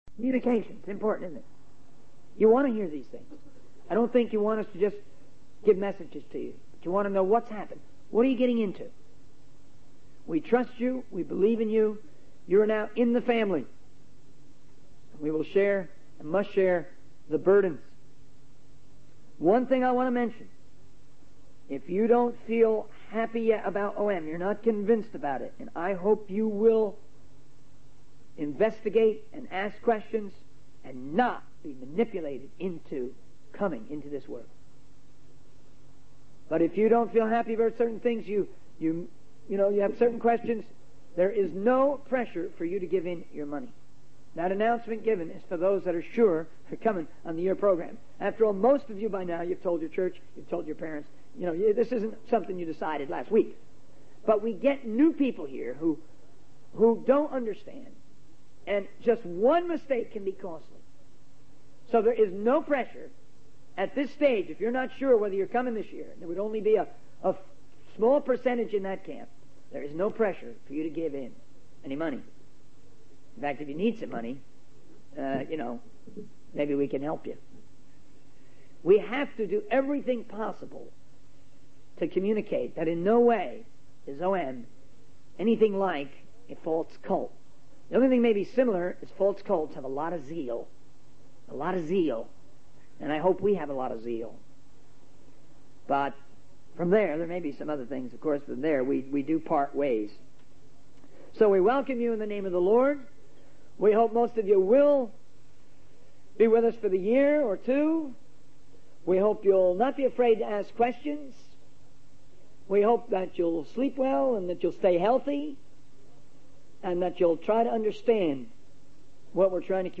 In this sermon, the speaker emphasizes the importance of seeking and obeying the will of God.